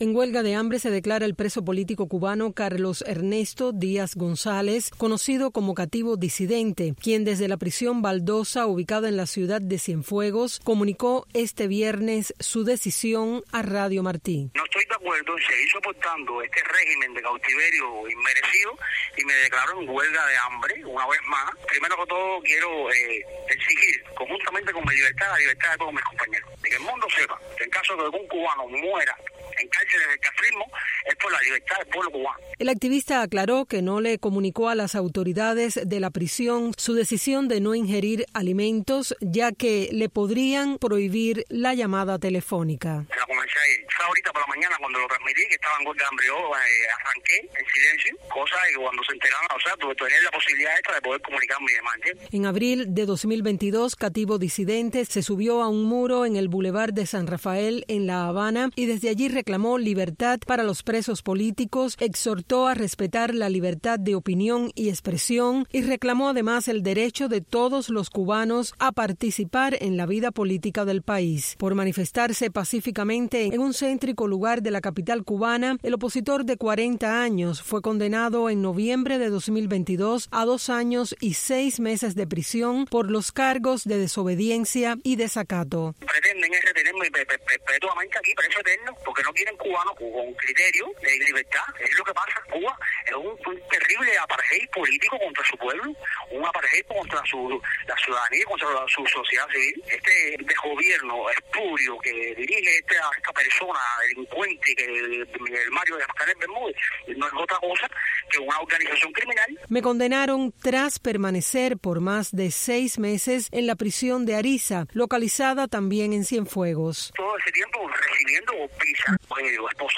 El opositor aclaró que no le comunicó a las autoridades de la prisión su decisión ya que podrían prohibirle la llamada telefónica.